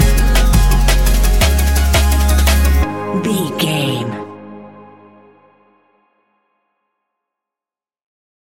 Ionian/Major
G♯
electronic
techno
trance
synthesizer
synthwave
instrumentals